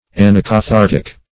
anacathartic \an`a*ca*thar"tic\